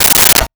Plastic Plate 04
Plastic Plate 04.wav